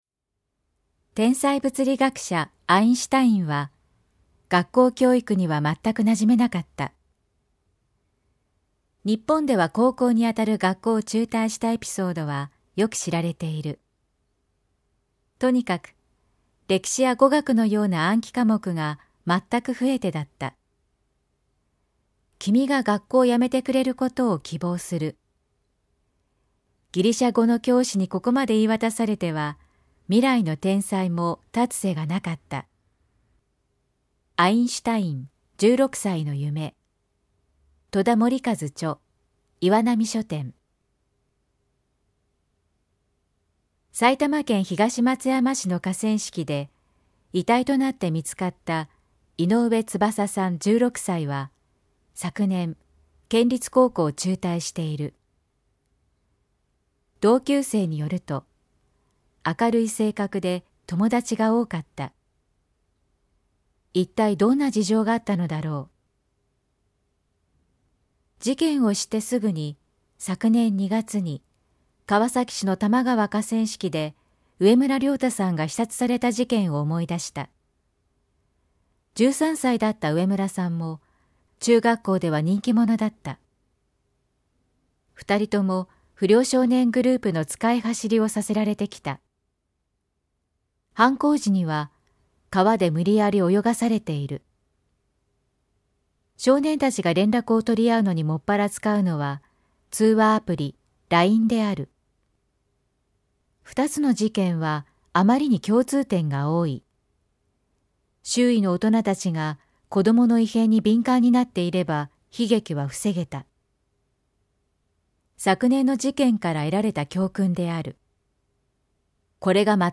産経新聞1面のコラム「産経抄」を局アナnetメンバーが毎日音読してお届けします。